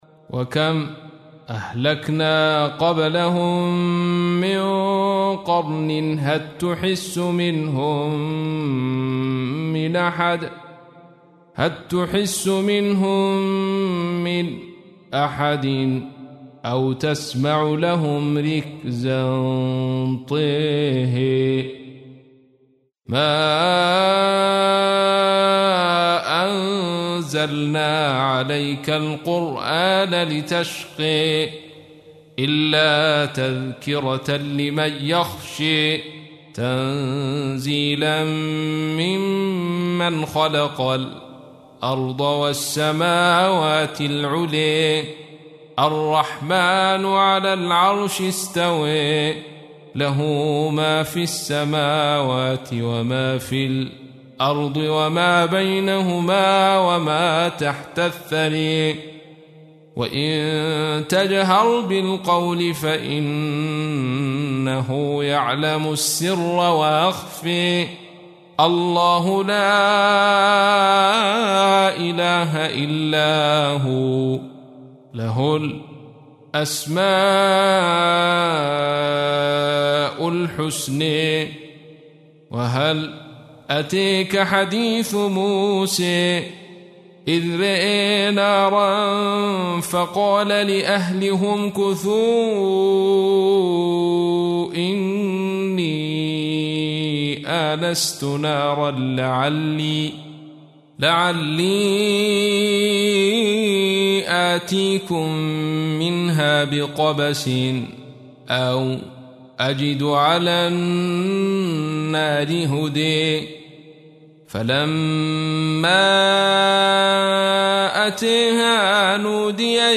تحميل : 20. سورة طه / القارئ عبد الرشيد صوفي / القرآن الكريم / موقع يا حسين